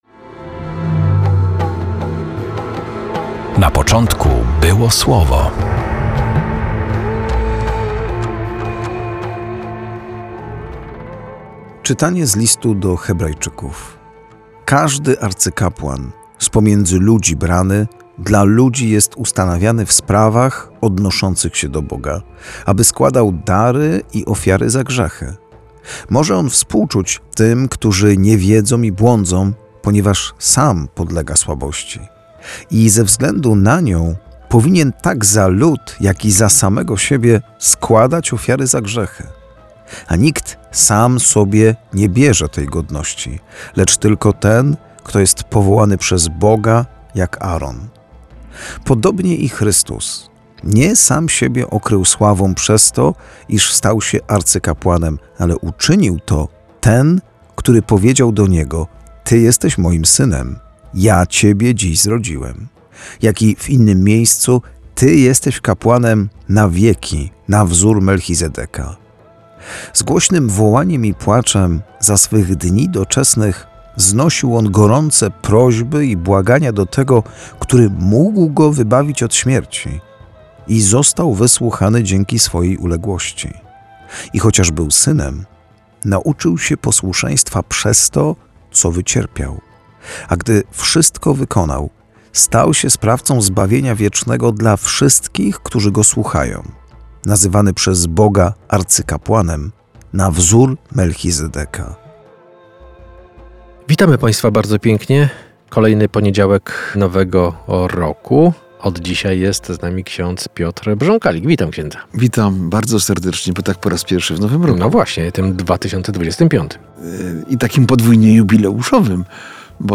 Perły ukryte w liturgii słowa odkrywają księża